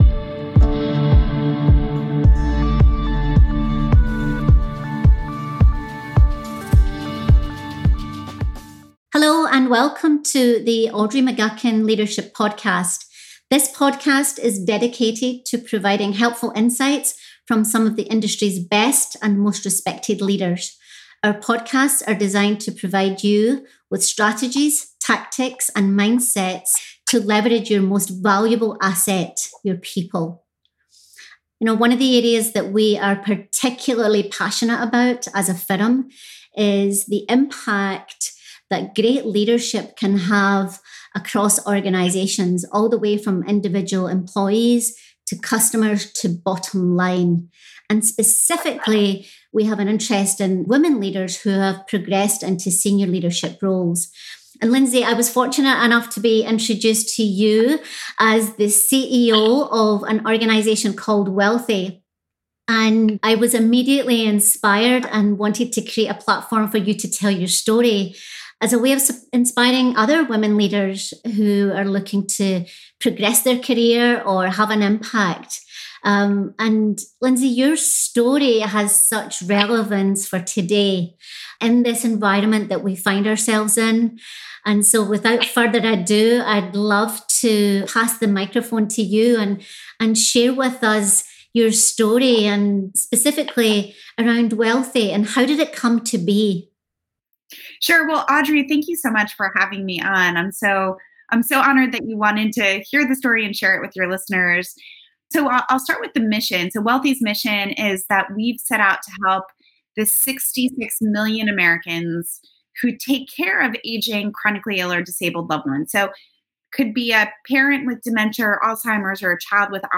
Hear the perspectives of two women leaders on empathy, their journey to CEO, and developing women leaders.